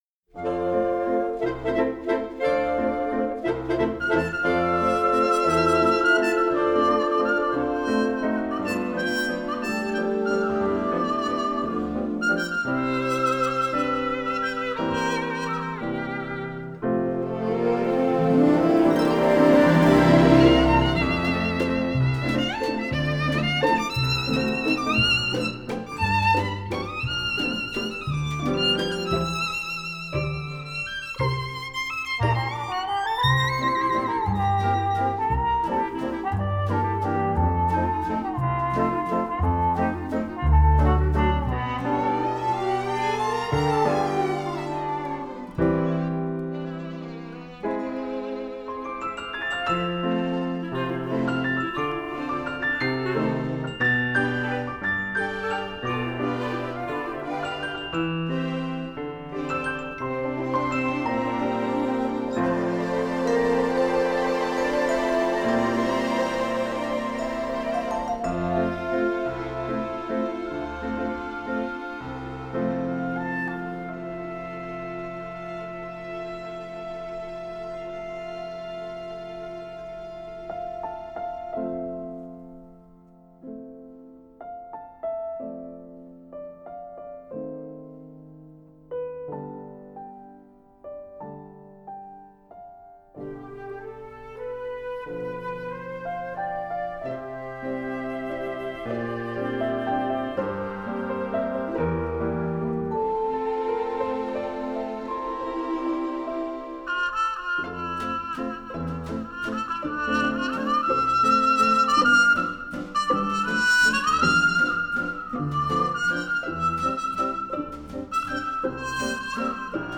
Soundtrack
Instrumental